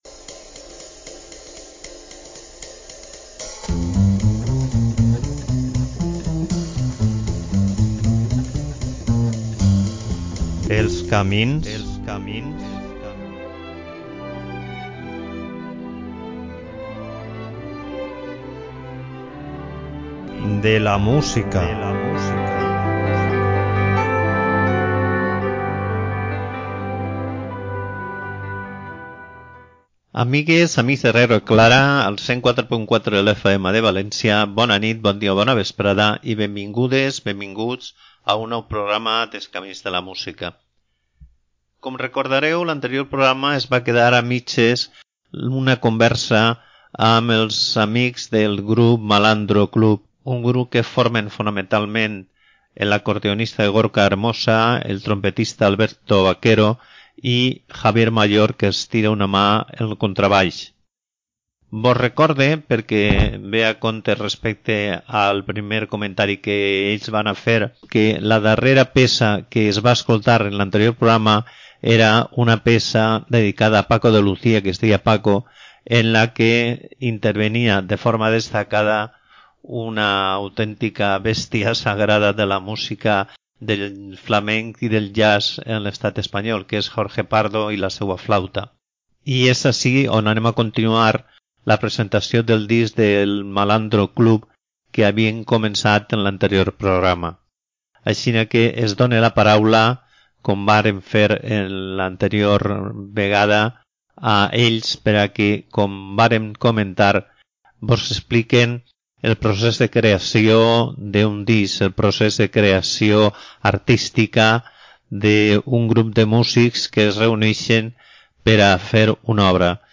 Escoltar a dos músics en conversa sobre com es construeix la seua música crec que és una experiència que, almenys per a mi, ha resultat enormement estimulant.